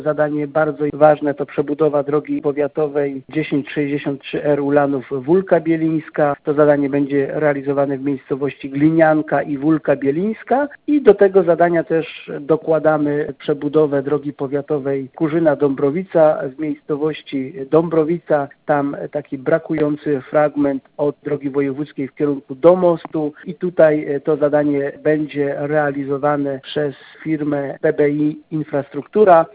Już została podpisana umowa na to zadanie i przekazany plac budowy. Mówi starosta niżański Robert Bednarz.